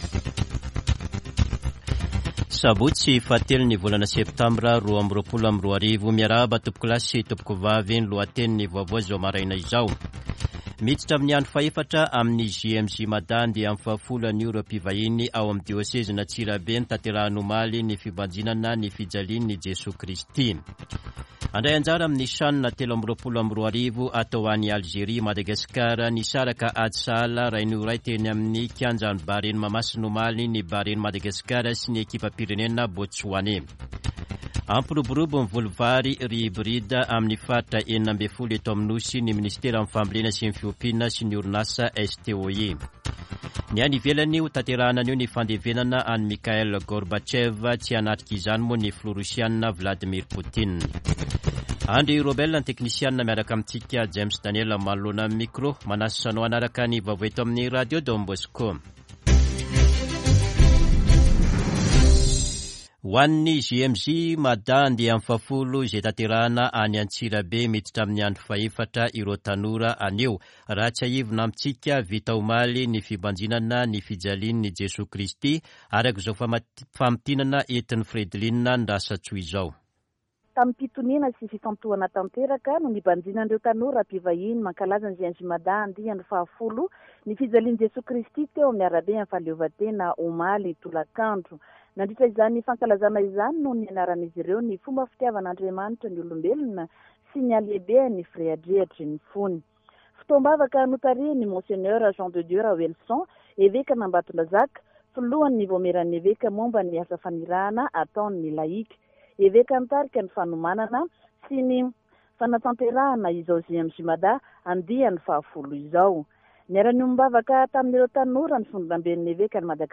[Vaovao maraina] Sabotsy 03 septambra 2022